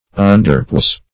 underpoise - definition of underpoise - synonyms, pronunciation, spelling from Free Dictionary
Search Result for " underpoise" : The Collaborative International Dictionary of English v.0.48: Underpoise \Un`der*poise"\, v. t. To weigh, estimate, or rate below desert; to undervalue.
underpoise.mp3